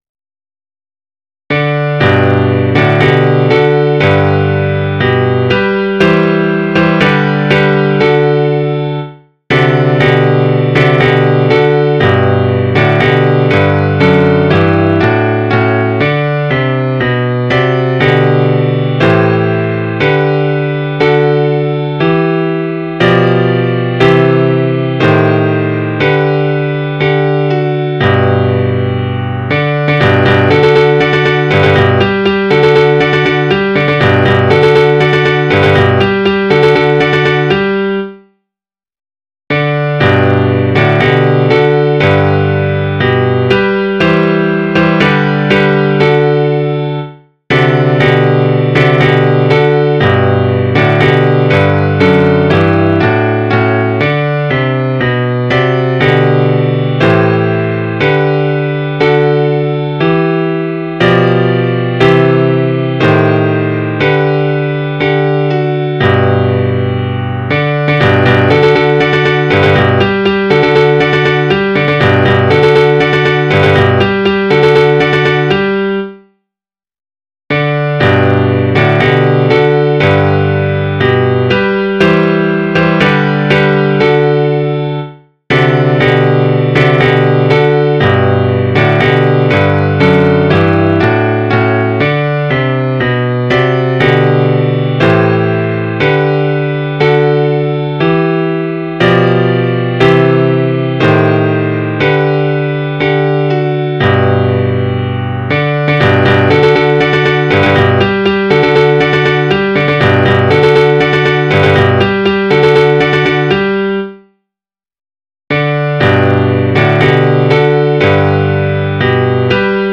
Midi File